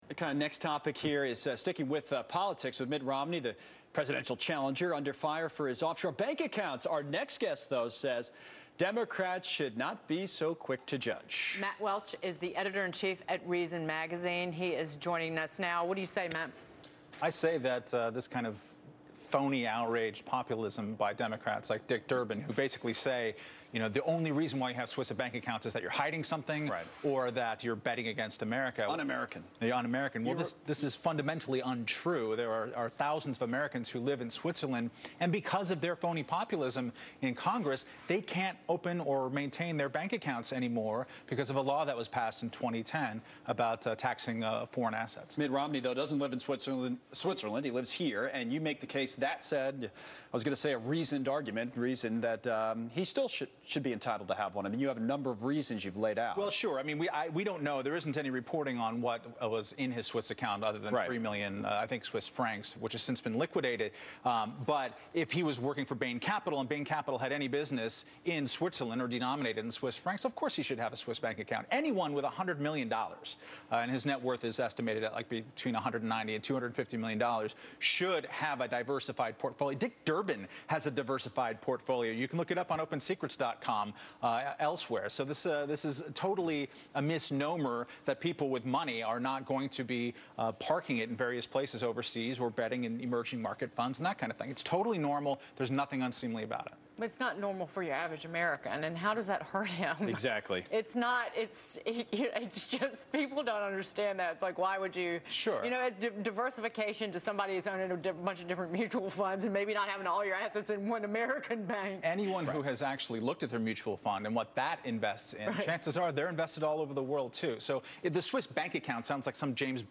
On Tuesday, July 10, Editor in Chief of Reason Matt Welch went on Fox Business to talk about Democratic outrage over Mitt Romney's Swiss bank account, and how this fake populism ignores the real issues of how taxpayer money is spent.